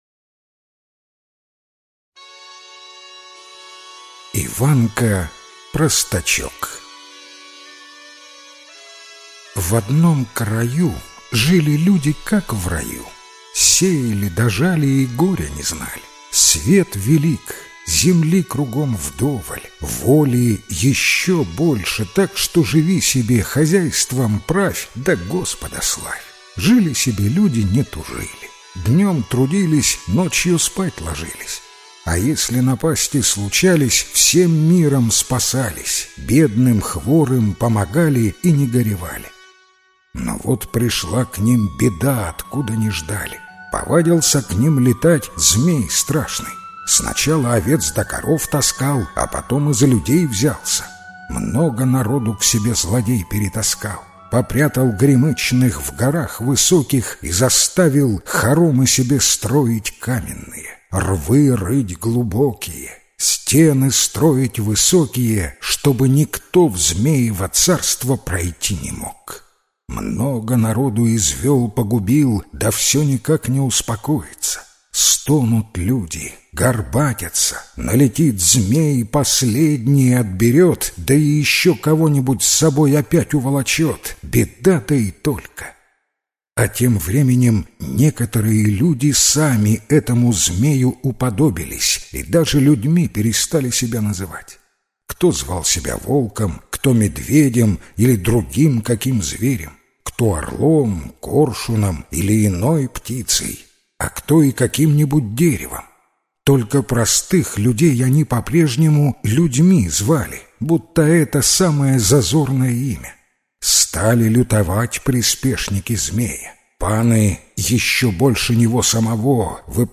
Иванка-простачок - белорусская аудиосказка - слушать онлайн